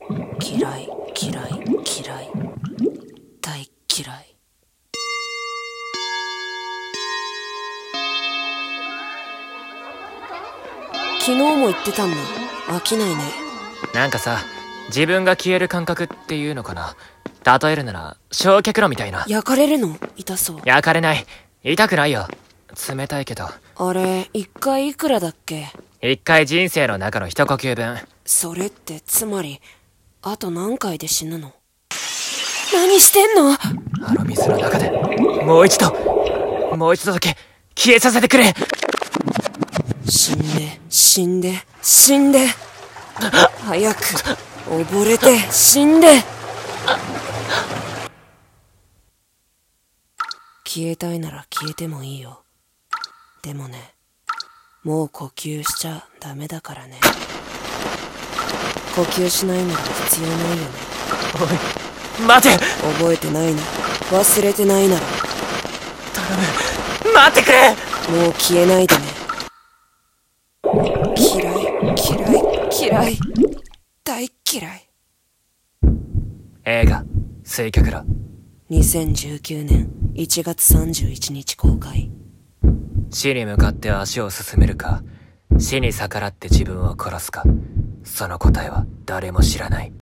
★水却炉【声劇台本